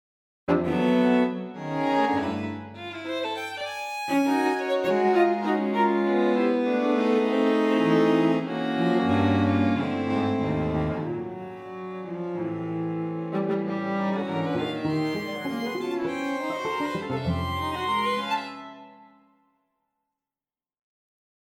Here is the same Berg with the Cremona String Quartet (my own expression maps).
that Cremona quartet sounds very good! is that being played back through Dorico?
Yes, it’s just straight Dorico; I pretty much just entered the first page of the score did next to no editing. I shortened the notes at the end of bar 4 to reflect the pause, and that’s about it.